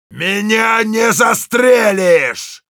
Sounds Yell Rus
Heavy_yell13_ru.wav